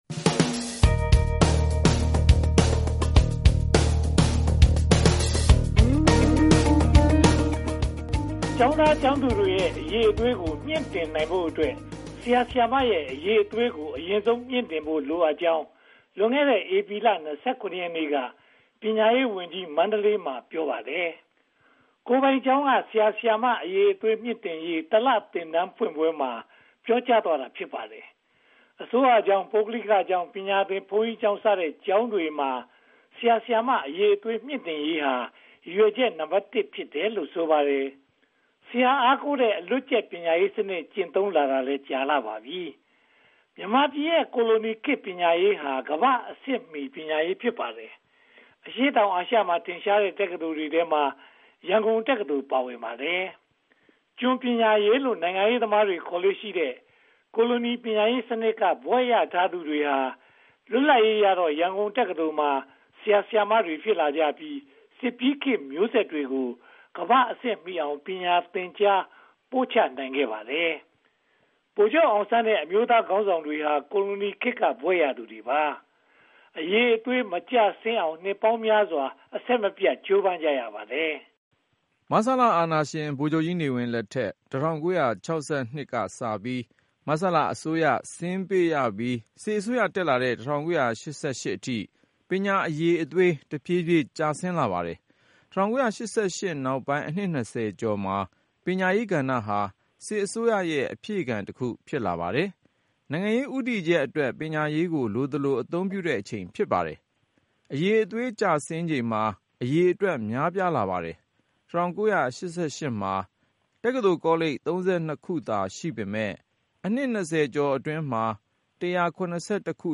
သတင်းသုံးသပ်ချက်